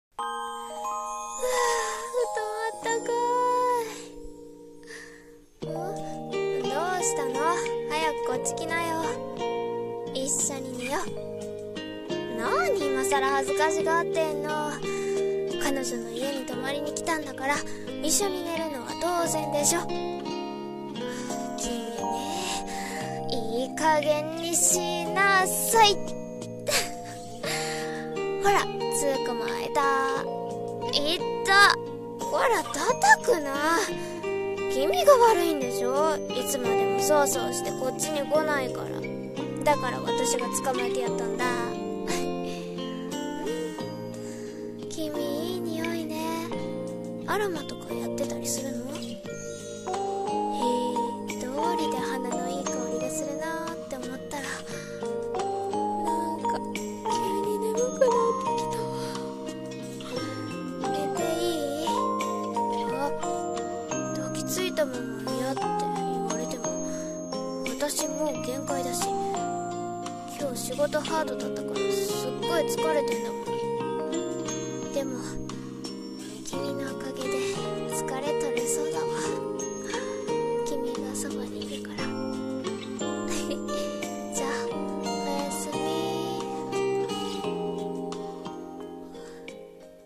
一人声劇台本｢添い寝CD風声劇｣